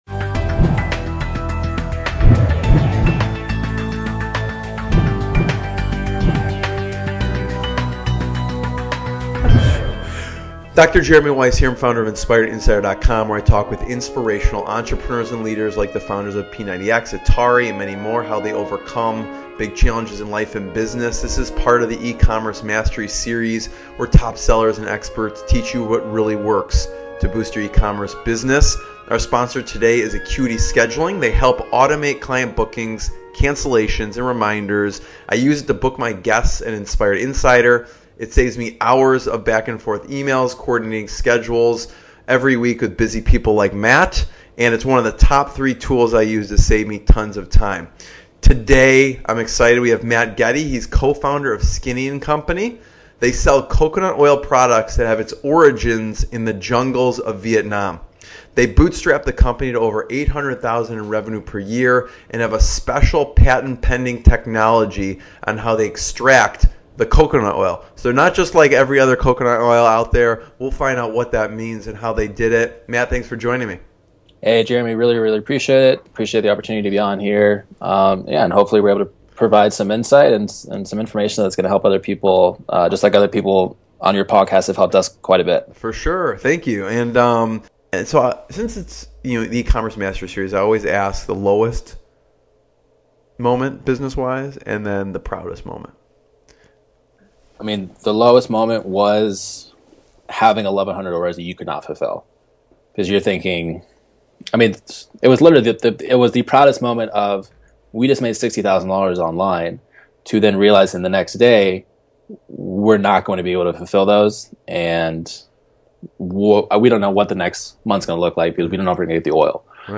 Inspirational Business Interviews